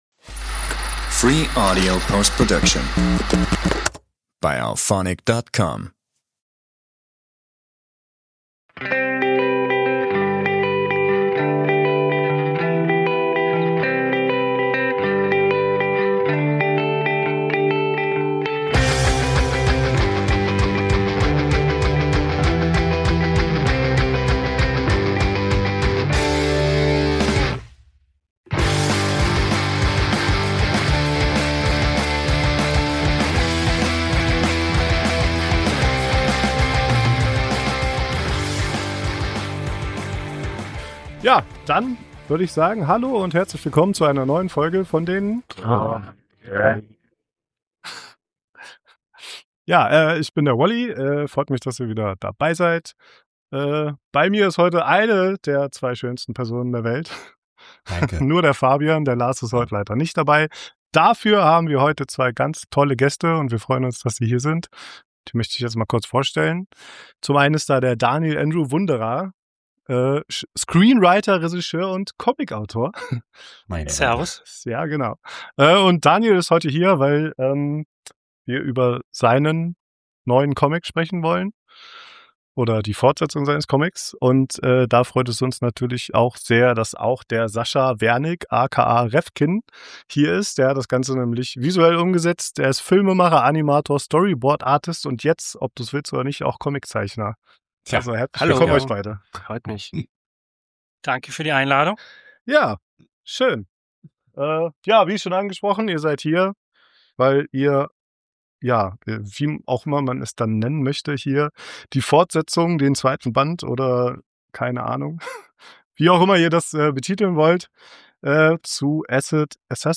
Für die Daddies ist dies Anlass genug um die beiden zu einem entspannten Interview-Talk einzuladen und mit Fragen zum Schaffensprozess eines Comics zu löchern.